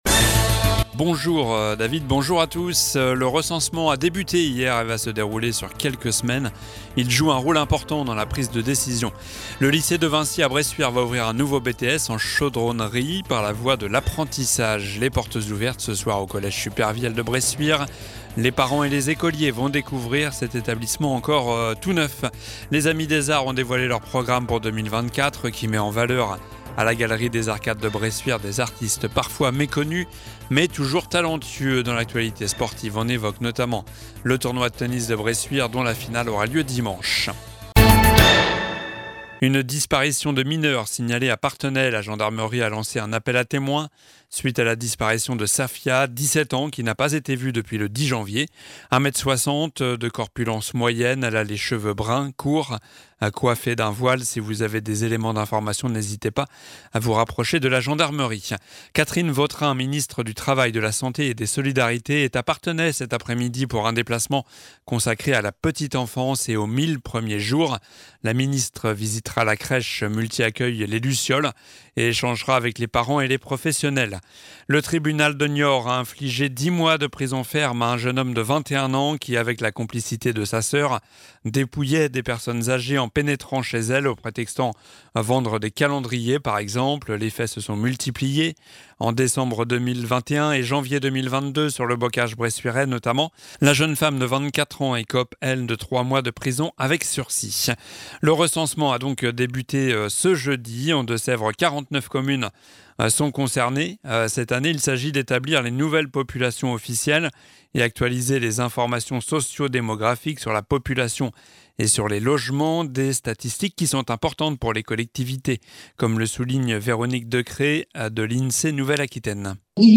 Journal du vendredi 19 janvier (midi)